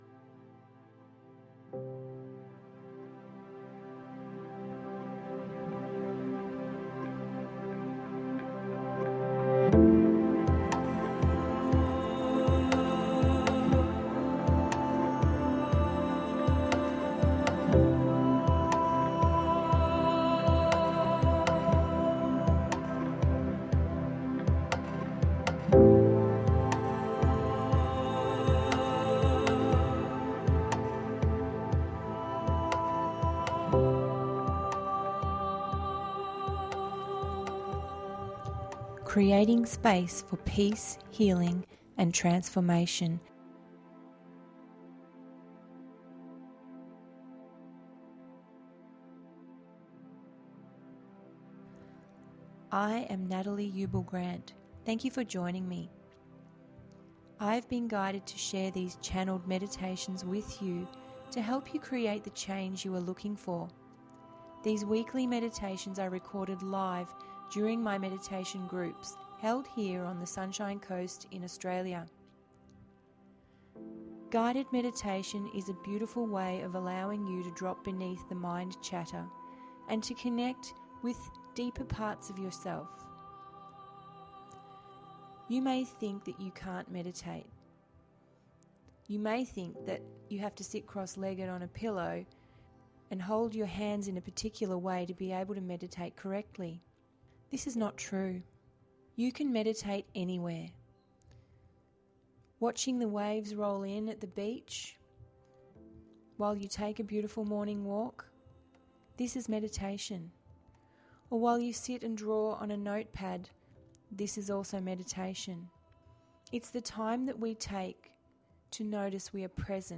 Guided Meditation duration approx. 24 mins